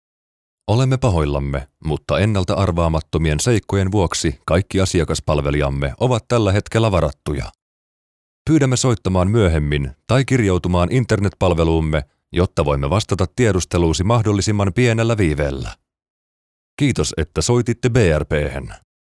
Deep and masculine Finnish voice over talent.
Versatile voice from ultra low to high midrange.
Sprechprobe: Industrie (Muttersprache):